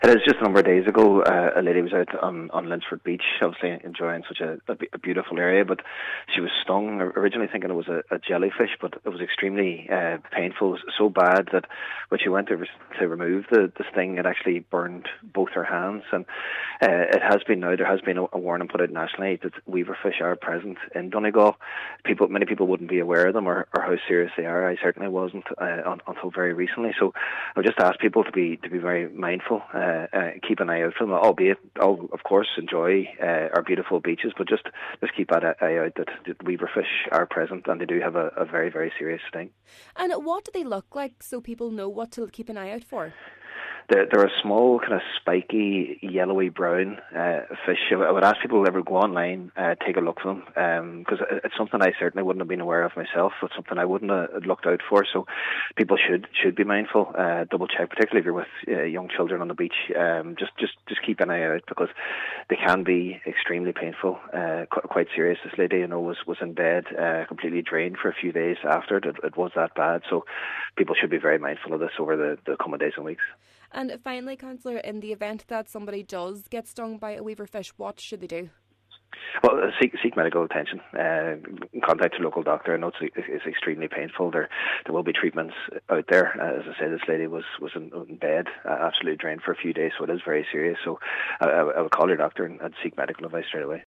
Cllr Murray says she became ill following the incident: